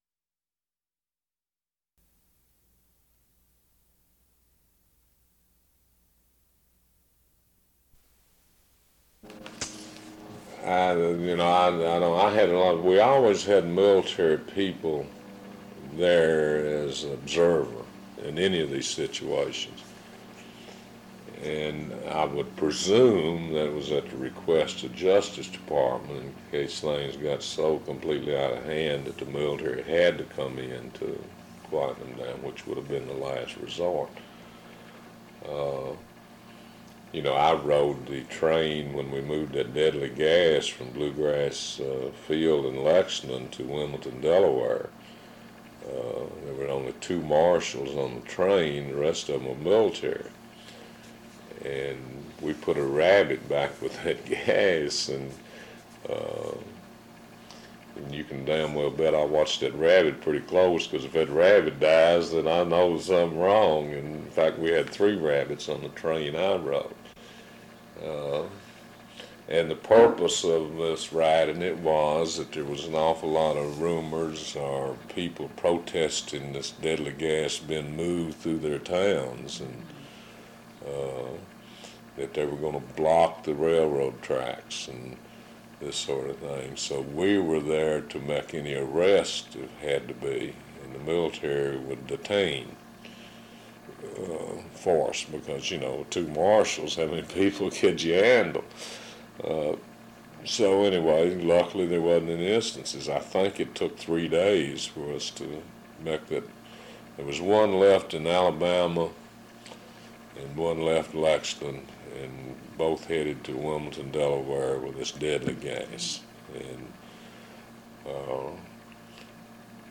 Kentucky Historical Society